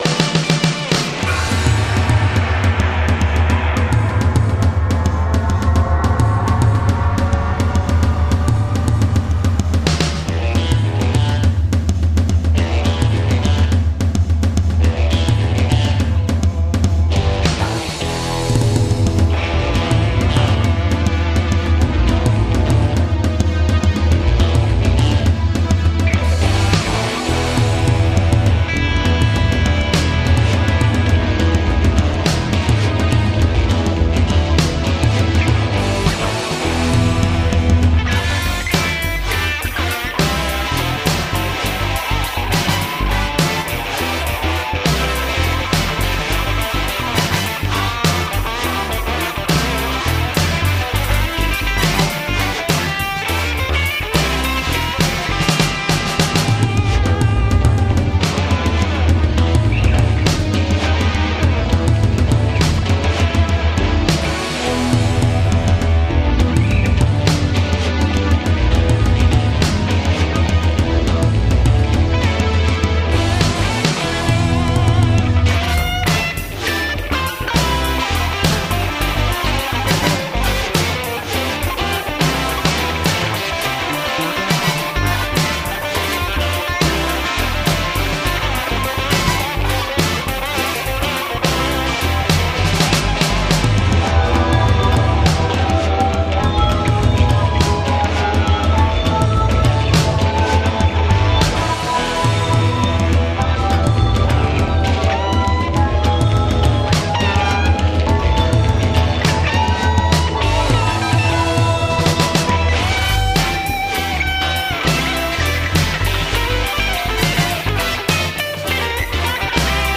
Drums and Percussion